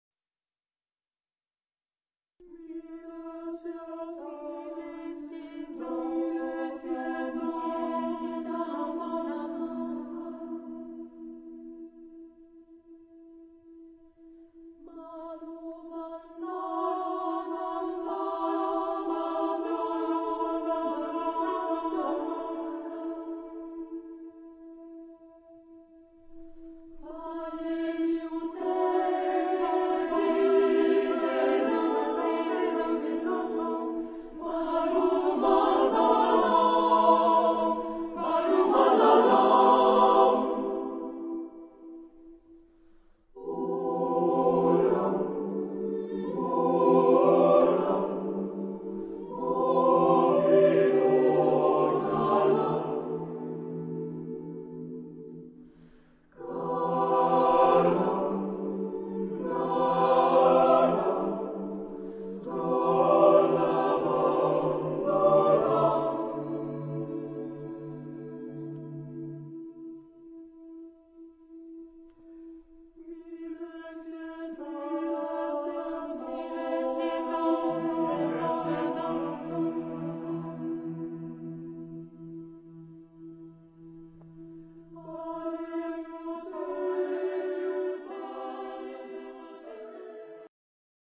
Texte en : onomatopées